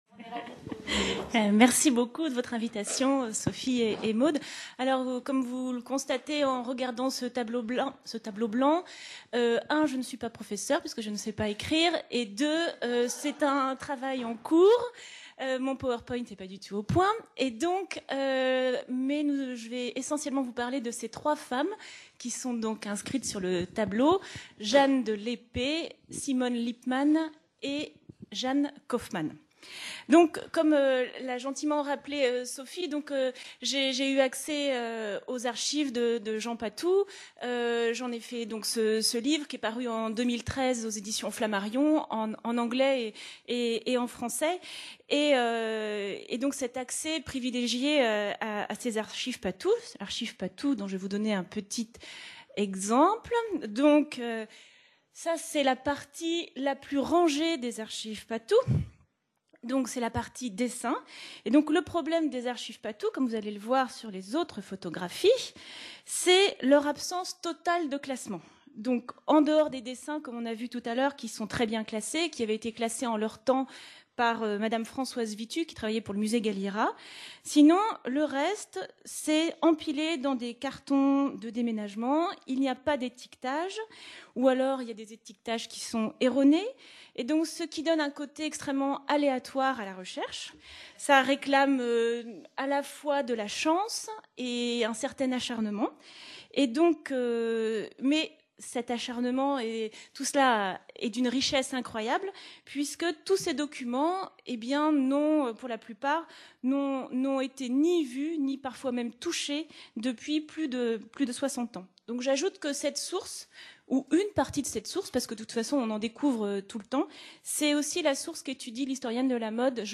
Séminaire histoire de mode. Les Femmes Qui Font la Mode : Directrices, Couturières, Petites Mains.